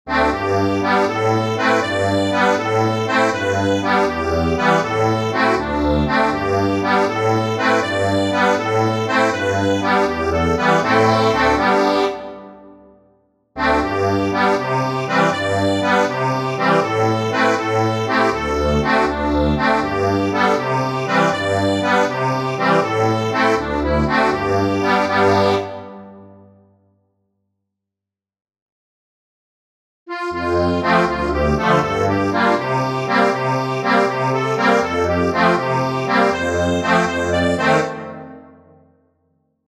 Easy accordion arrangement plus lyrics.